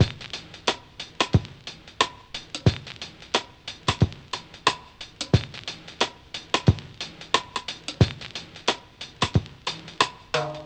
RAGGAVINYL-R.wav